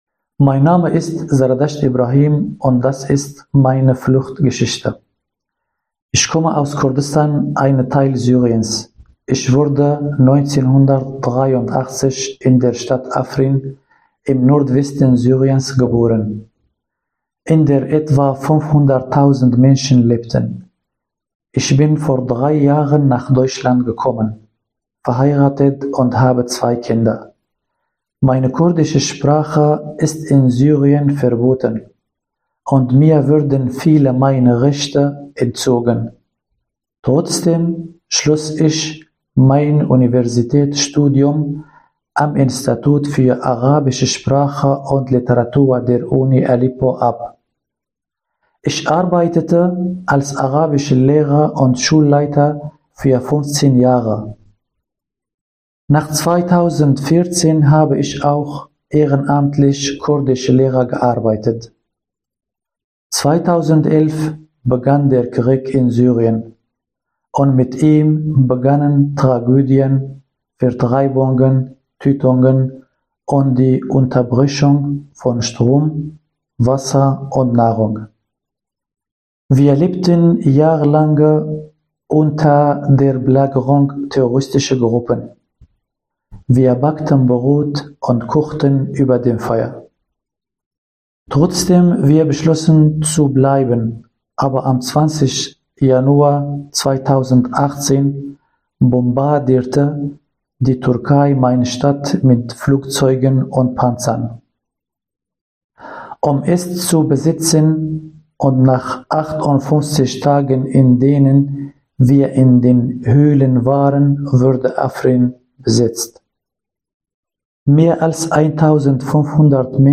Bei den Aufnahmen, die oftmals in den Räumen der Waschbar stattfanden, ging es nicht um Perfektion, sondern um das wirkliche Leben. So begleiten schon mal Kirchenglocken oder auch Baustellengeräusche das gesprochene Wort.